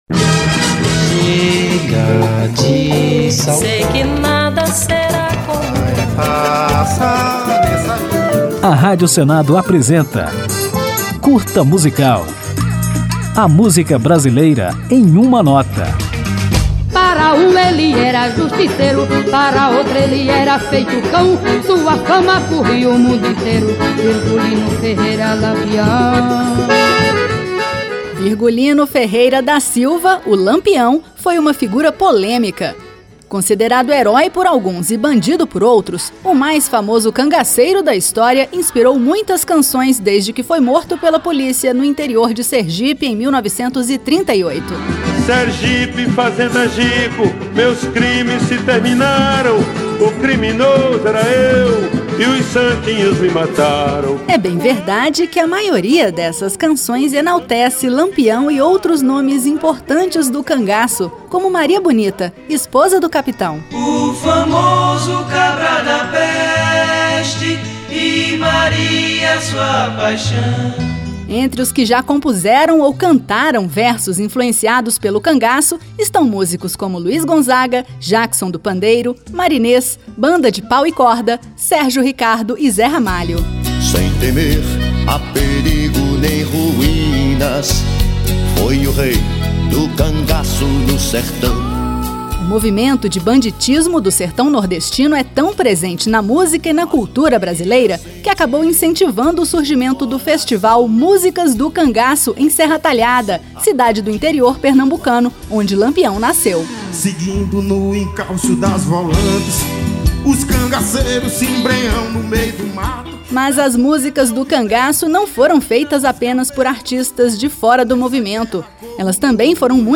Conjunto de pílulas radiofônicas sobre a MPB, nas quais o ouvinte pode conferir fatos, curiosidades, informações históricas e ainda ouvir uma música ao final de cada edição.